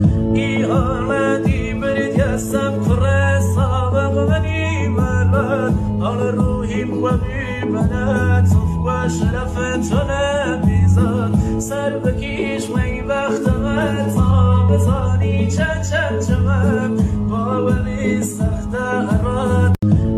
moosighi pop